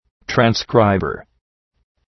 Προφορά
{træn’skraıbər} (Ουσιαστικό) ● αντιγραφέας